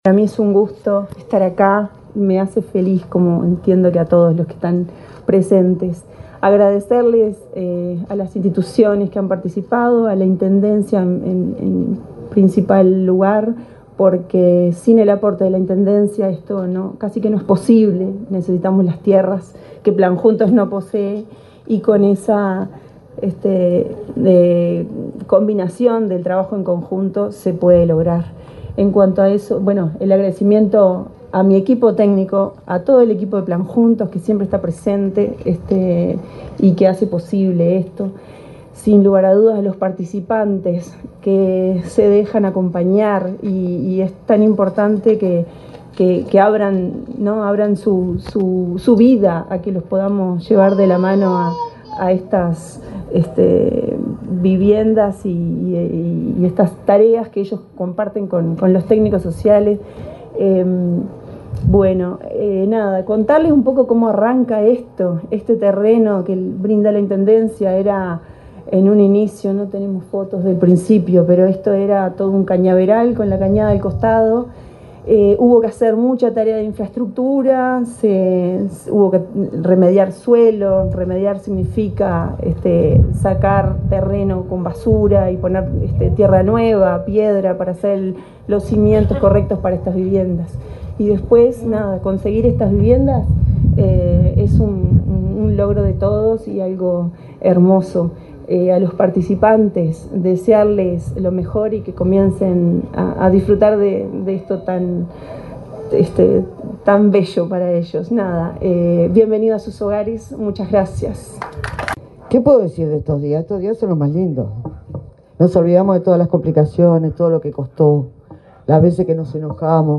Palabras de autoridades del Ministerio de Vivienda
Palabras de autoridades del Ministerio de Vivienda 11/04/2025 Compartir Facebook X Copiar enlace WhatsApp LinkedIn La coordinadora general del plan Juntos, Inés Sarríes, y la ministra de Vivienda, Cecilia Cairo, se expresaron, este viernes 11, durante el acto de entrega de 17 viviendas en el barrio Punta de Rieles, en Montevideo.